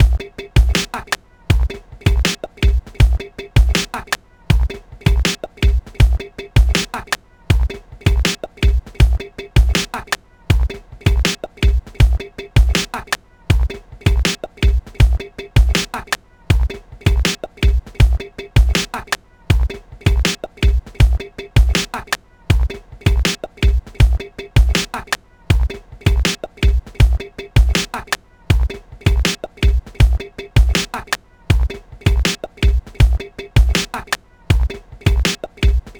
ご用意したのは、ステレオの一般的なループ音源と、POシリーズに同期をして楽しめるクリック入りのシンクモードのループ音源です。
マイクロ・サンプラー
BPM 80（HIP-HOPモード）